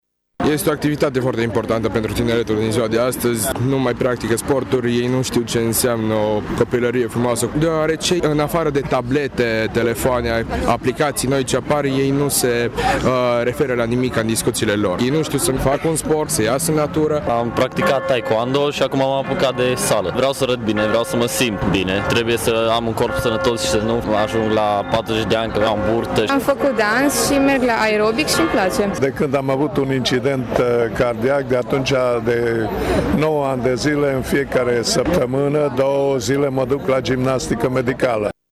Puținii tinerii prezenți la marș au declarat că sunt conștienți de necesitatea mișcării fizice, atât pentru a arăta bine cât și pentru menținerea sănătății.
La fel și cei mai în vârstă, care după 50-60 de ani susțin că nu e târziu pentru mișcarea fizică.